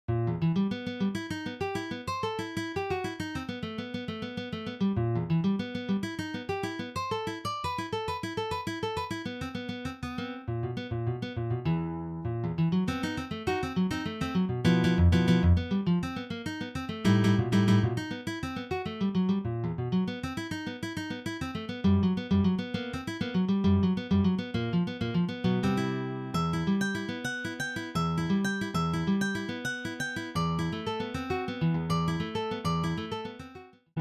Demo mp3